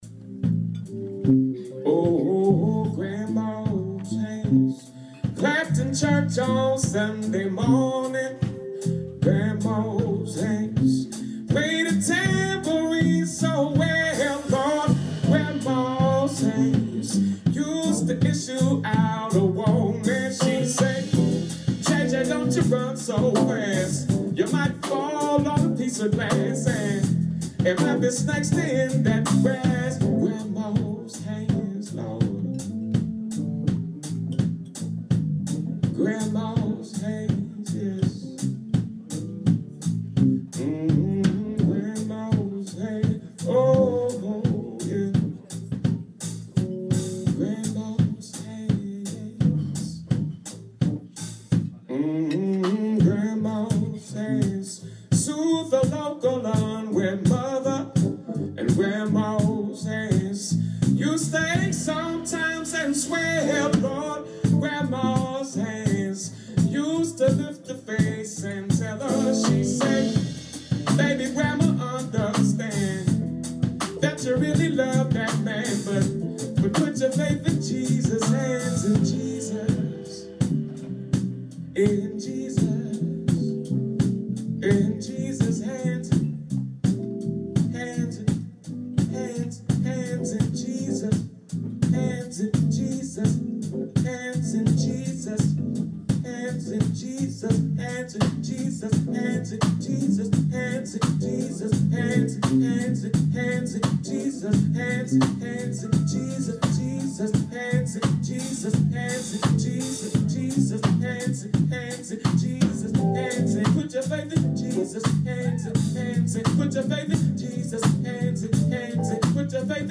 Live at Blue Note Jazz Bar, NYC, 14th May 2015